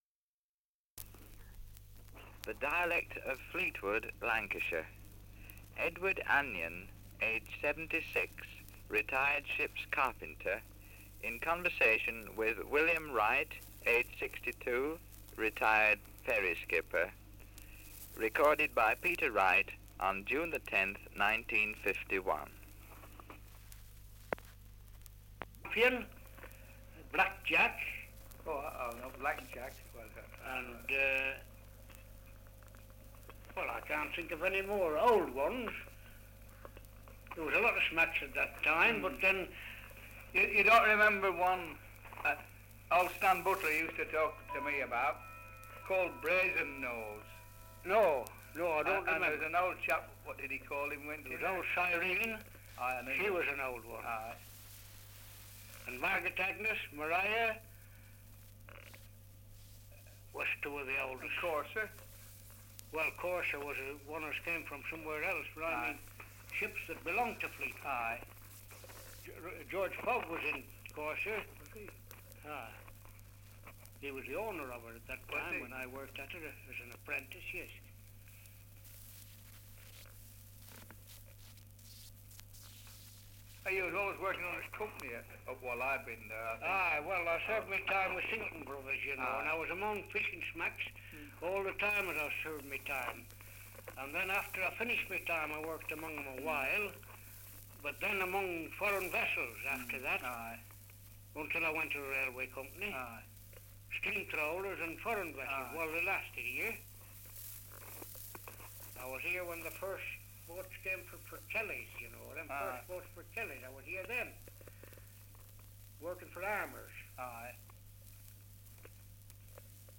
Survey of English Dialects recording in Fleetwood, Lancashire
78 r.p.m., cellulose nitrate on aluminium